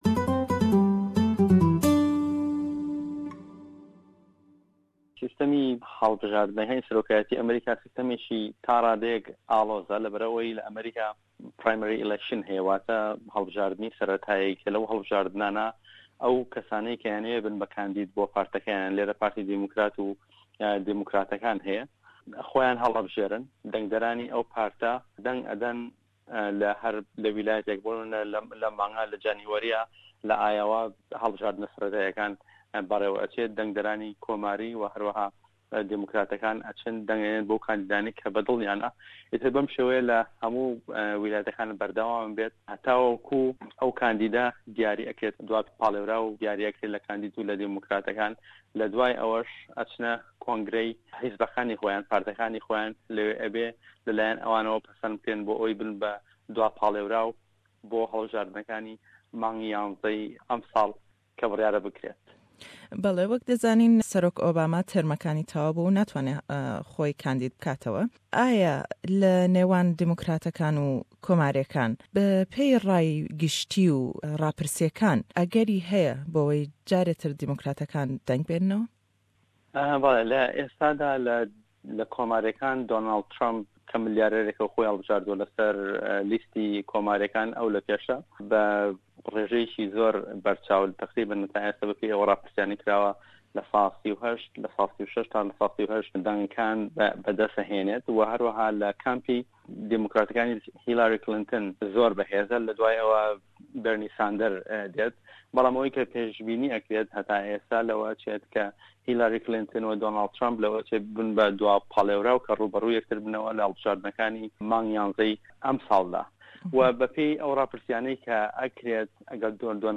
Le em hevpeyvîne da